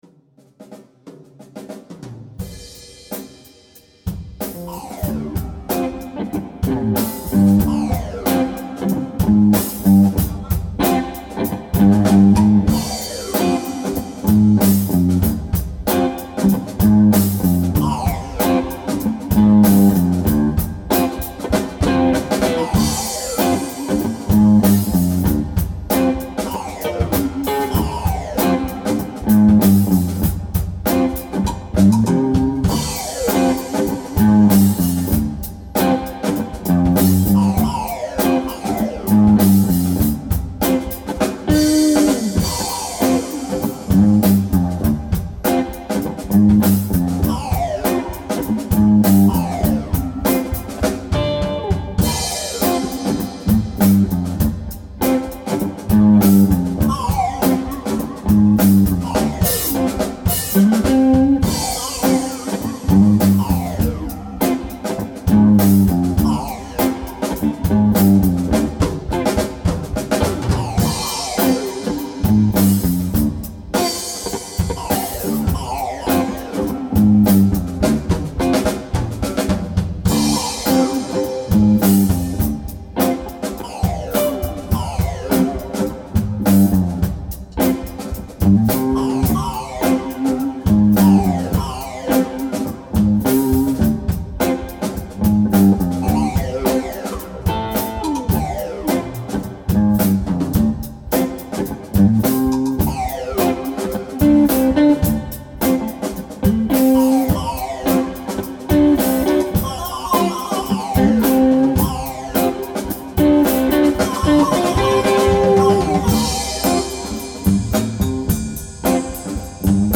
Tempo: 50 bpm / Date: 03.03.2015